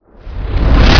boil_windup.wav